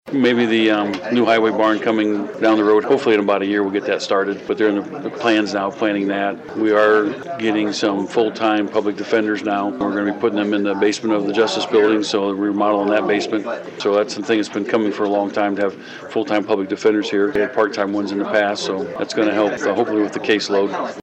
Following a recent meeting, Swick Broadcasting spoke with LaGrange County Commission President Terry Martin who said seeing work done on the roads around the County is something they are very proud of.